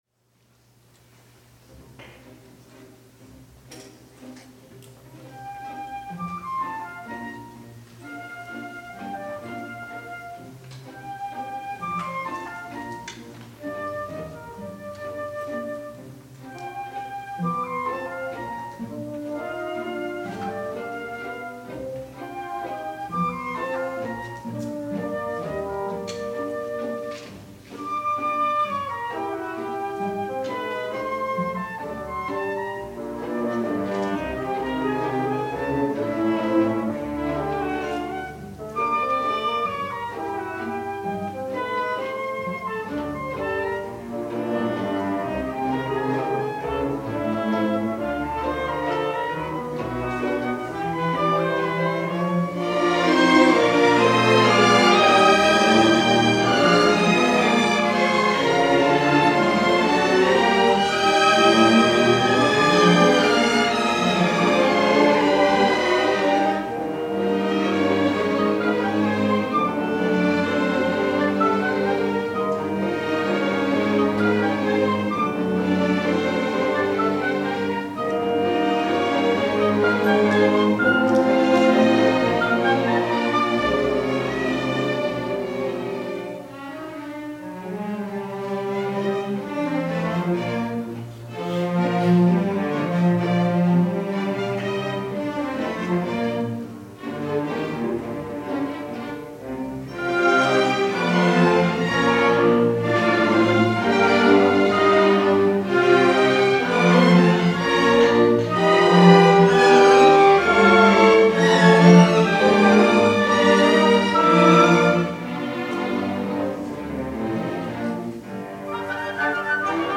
Santa Barbara City College Symphony Concert, Spring 2009
(Note - our high quality recording was plagued with errors and had to be scrapped. The audio files below are taken from our consumer grade movie camera.)